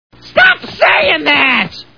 The Simpsons [Krusty] Cartoon TV Show Sound Bites